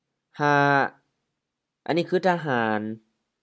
ทะหาน tha-ha:n H-M